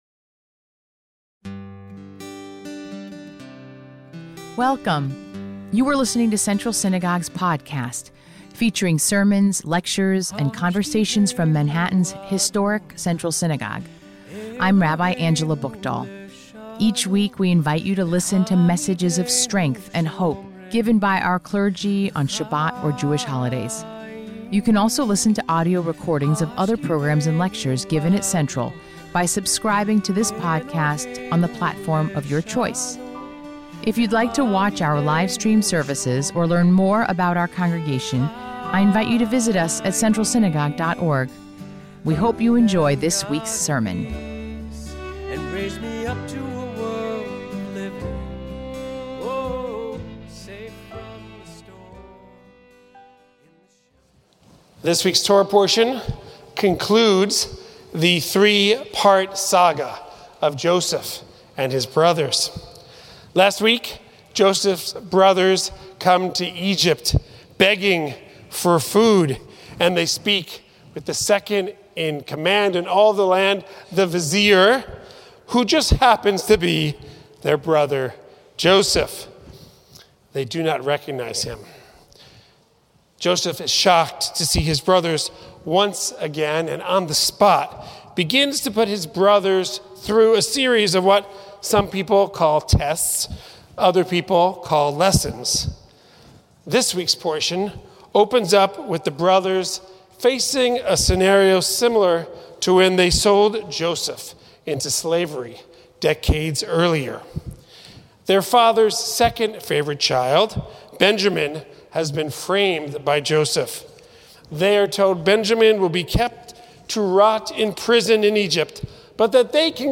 MEDITATION
Weekly Meditation